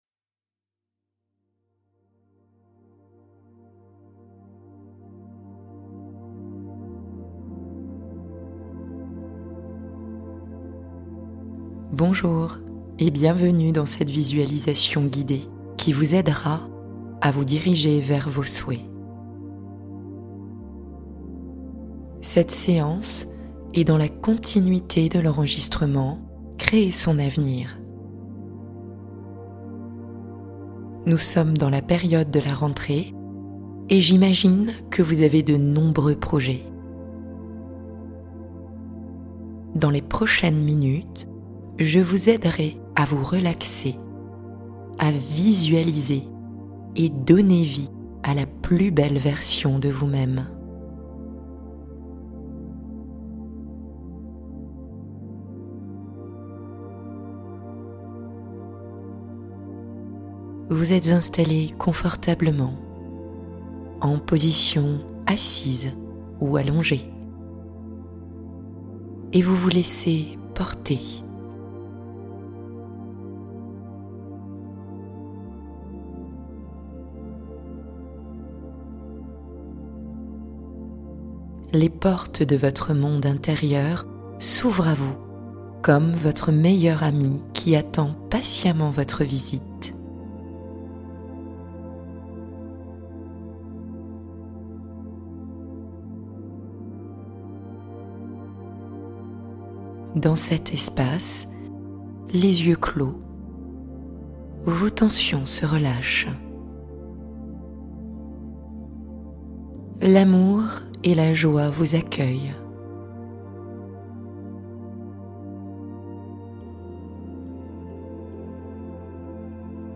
Créez l'avenir de vos rêves #2 | Méditation guidée de manifestation et visualisation puissante